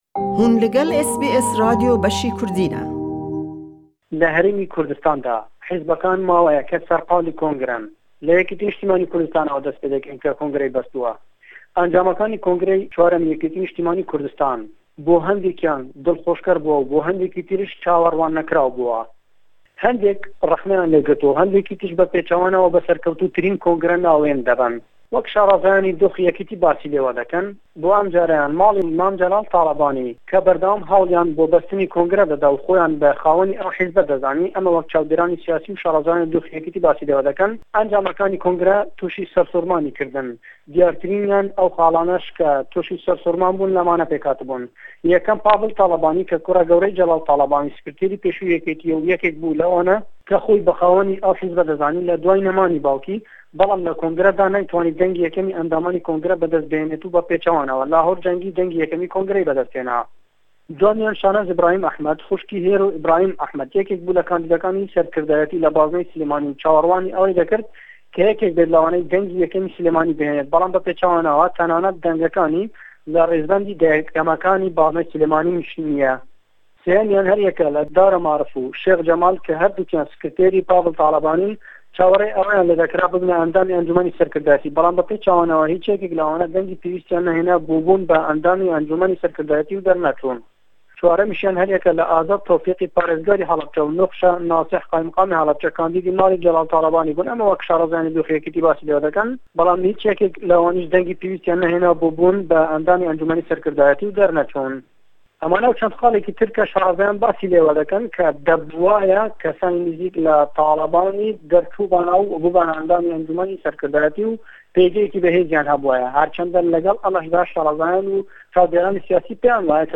Raporta ji Hewlêrê